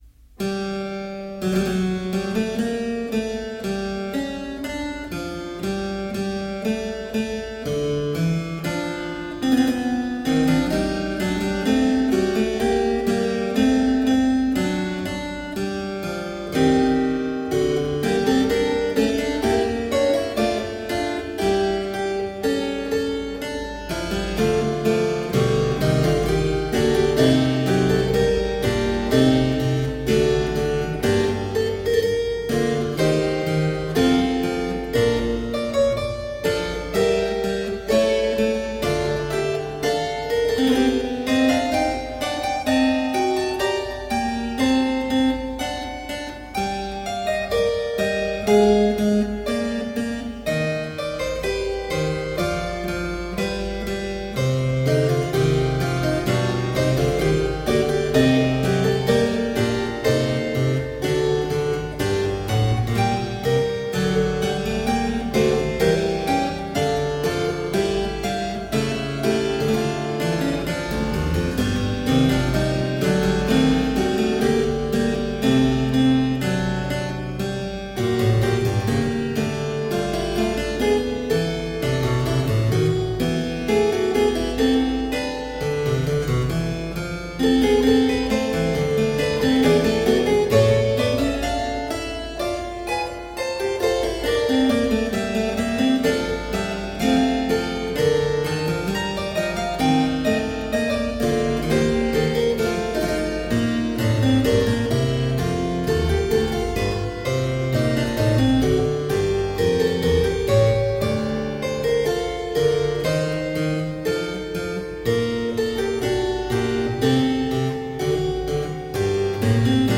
Solo harpsichord music
Tagged as: Classical, Baroque, Instrumental Classical
Harpsichord